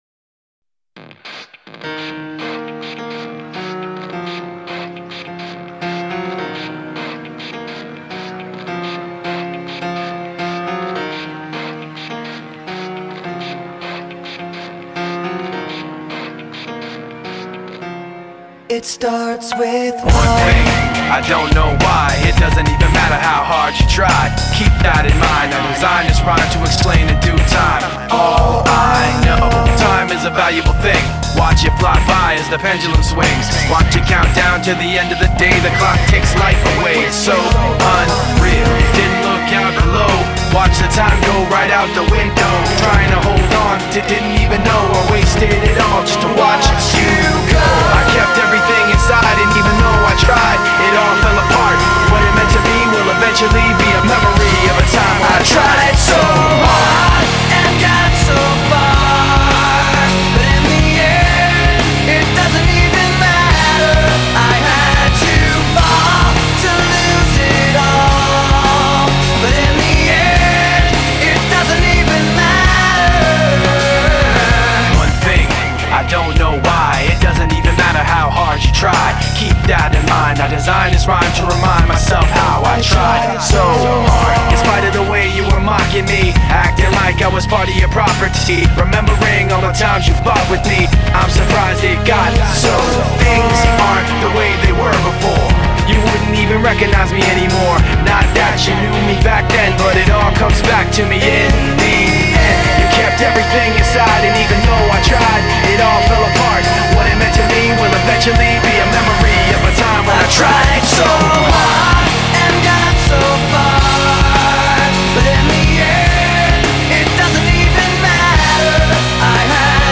2024-09-12 21:15:55 Gênero: Rock Views